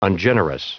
Prononciation du mot ungenerous en anglais (fichier audio)
Prononciation du mot : ungenerous